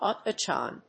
/ɔːˈtɒkθən(米国英語)/